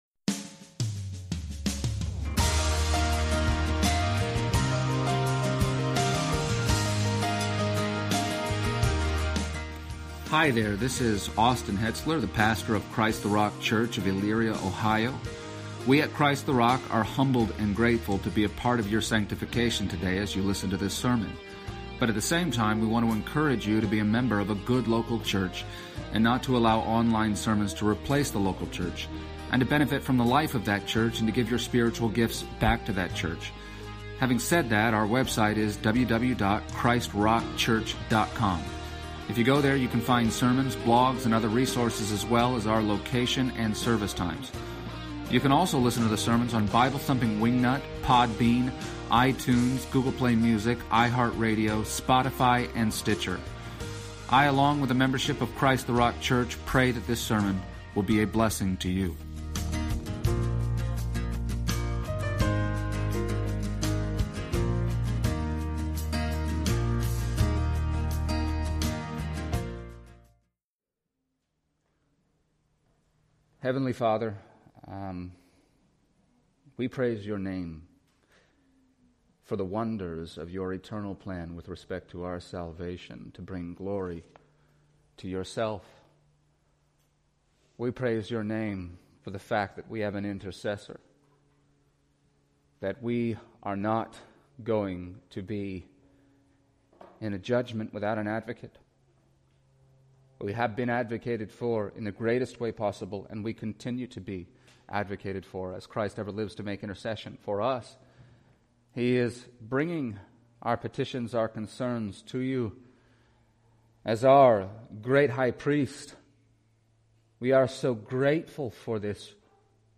Passage: John 17:20-26 Service Type: Sunday Morning